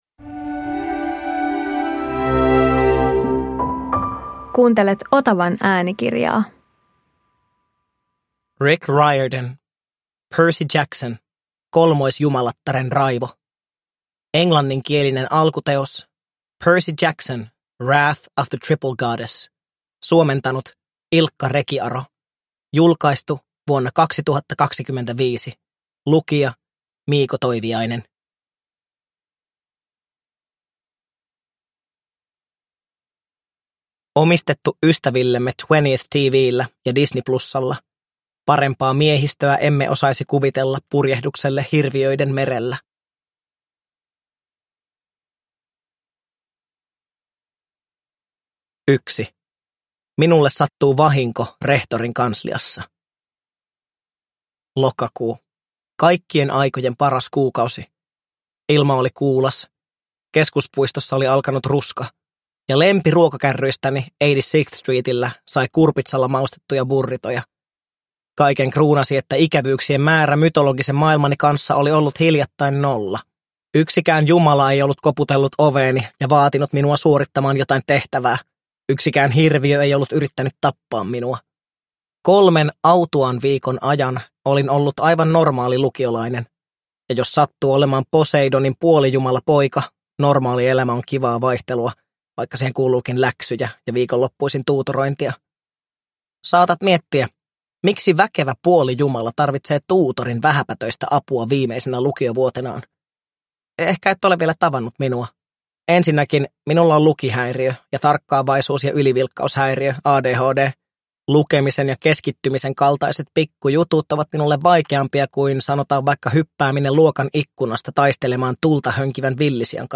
Percy Jackson – Kolmoisjumalattaren raivo – Ljudbok